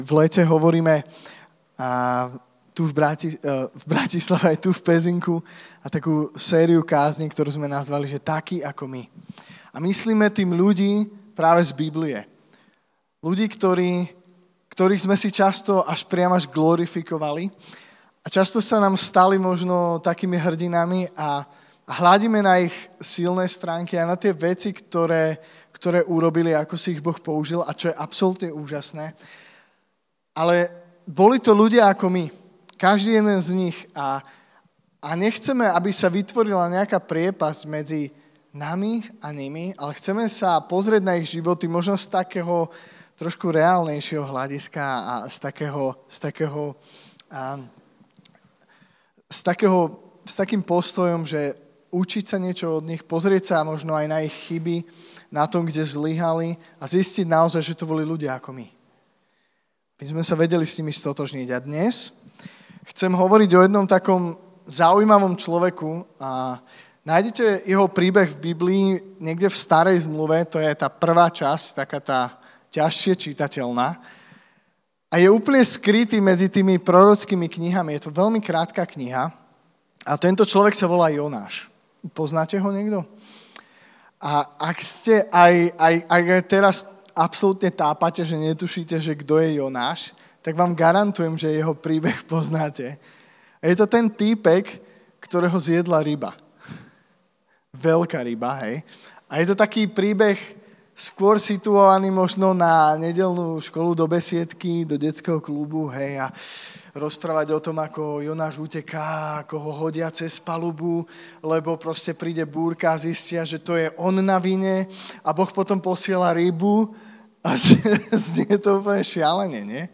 V pokračovaní letnej série kázní "TAKÍ, AKO MY" sme v našej lokalite CITYCHURCH PEZINOK hovorili o Jonášovi.